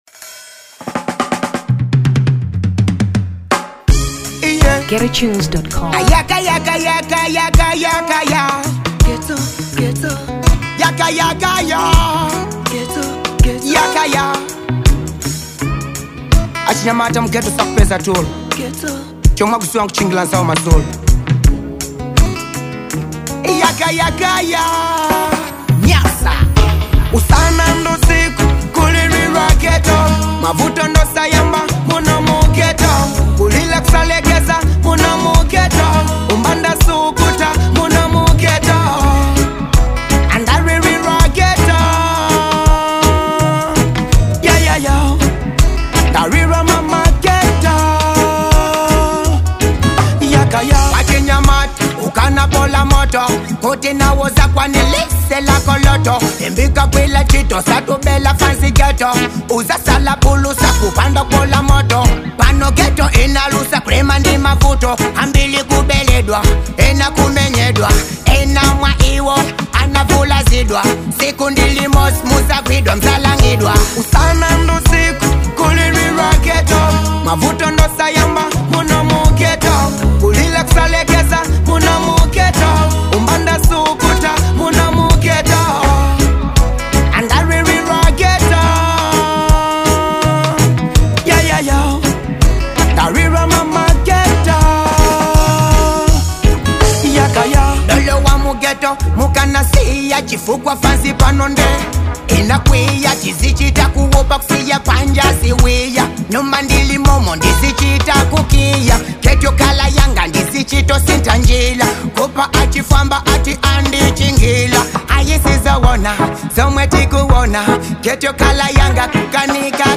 Reggae 2023 Malawi